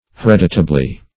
hereditably - definition of hereditably - synonyms, pronunciation, spelling from Free Dictionary Search Result for " hereditably" : The Collaborative International Dictionary of English v.0.48: Hereditably \He*red"i*ta*bly\, adv.